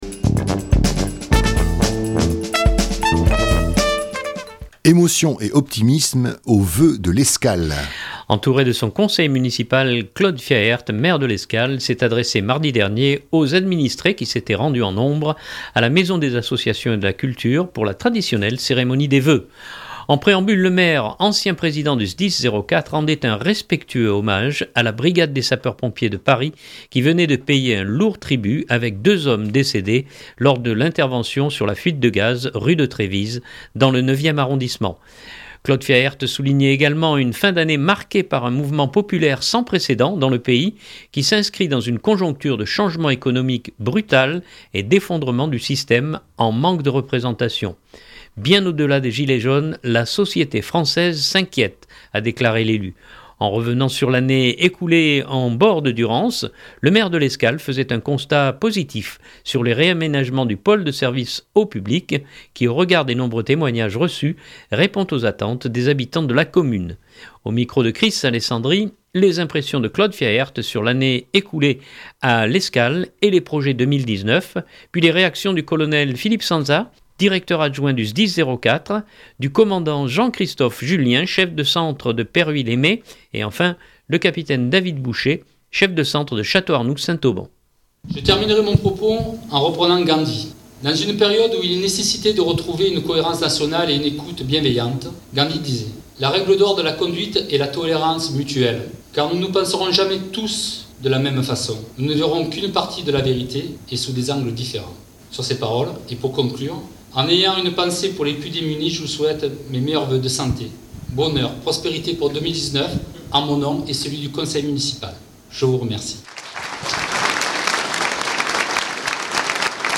(14.81 Mo) Entouré de son conseil municipal, Claude Fiaert maire de L’Escale s’est adressé mardi soir aux administrés qui s’étaient rendus en nombre à la Maison des Associations et de la Culture pour la traditionnelle cérémonie des vœux.